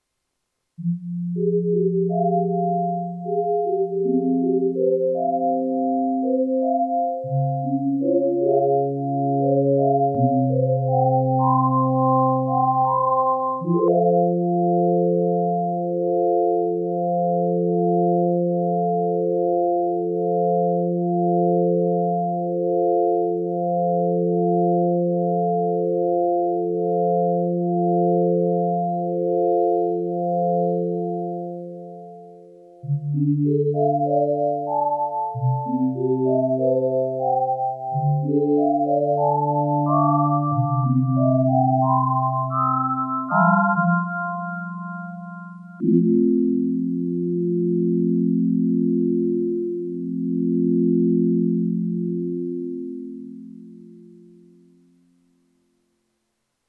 No effects were used, it's just a direct feed from the stereo analogue outputs.
3) Panning Modulation at audio rates -
Again, another very simple sound, using 2 oscs (sine waves), one with a slight bit of pitch EG (and no filter).
As I hold down the F major chord a few bars in, I start to adjust the Coarse tuning of Osc 4, which is modulating the Pan. At first it's at -60 semitones (sub-audio range), and then I gradually (more or less!) raise it all the way up to +60 semitones.